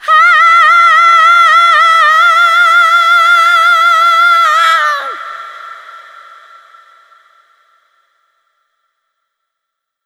SCREAM 6  -L.wav